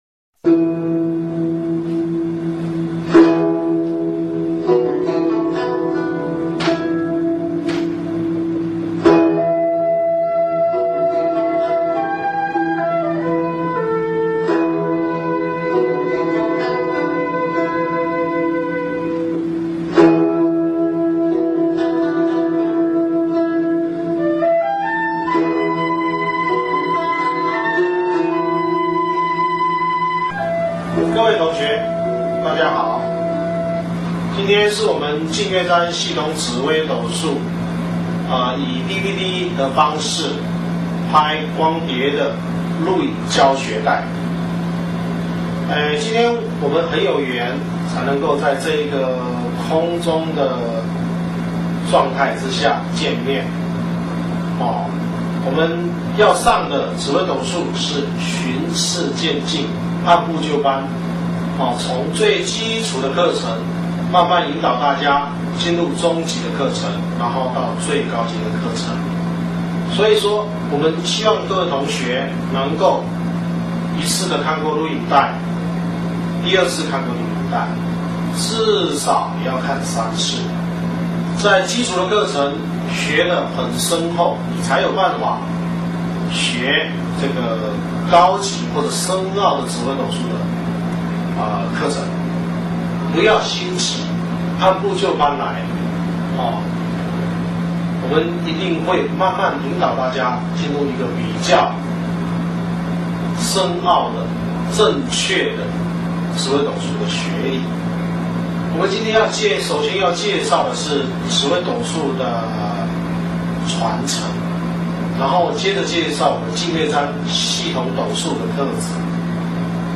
紫微斗数课程